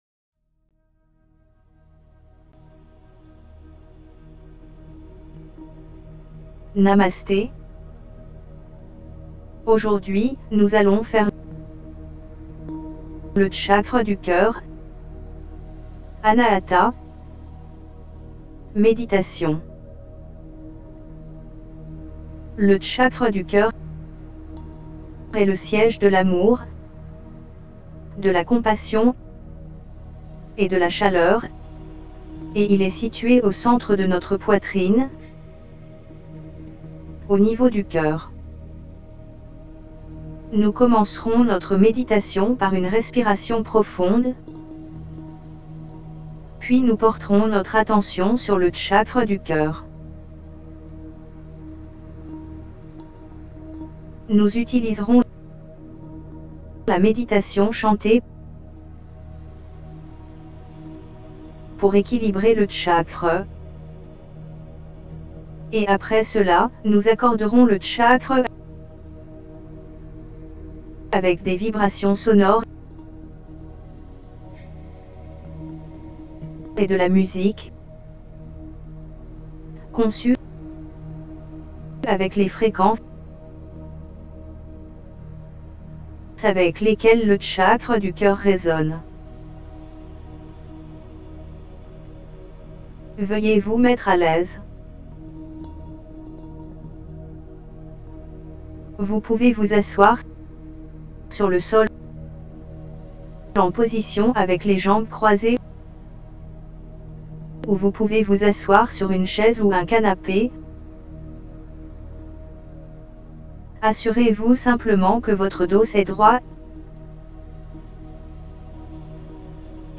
4HeartChakraHealingGuidedMeditationFR.mp3